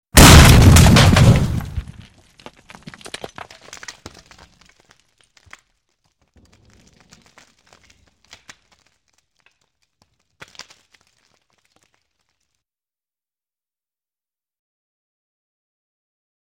Звук небольшого взрыва от кусочка динамита